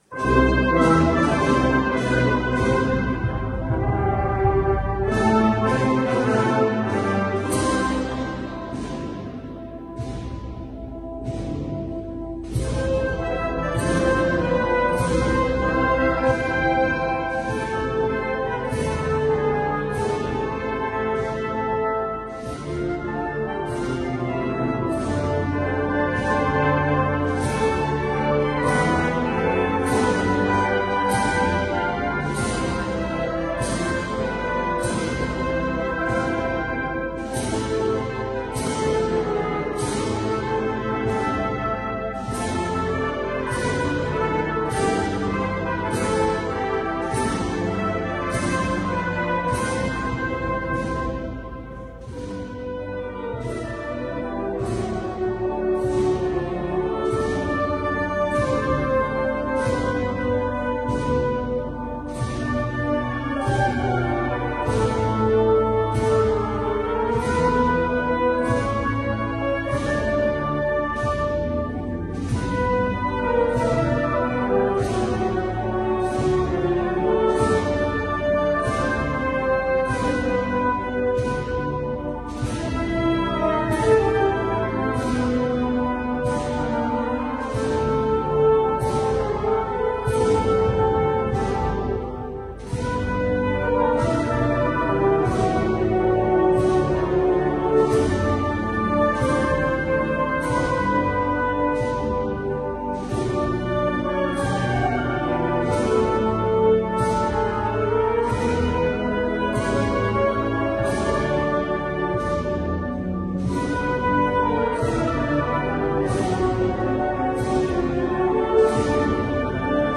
Marcha eucarística al Santísimo o Marcha procesional
piano / banda
Banda de Música Nuestra Señora de Gracia, Ajofrin [instrumentación: Ernesto Garcia de León]
paso de la custodia - banda ajofrin.mp3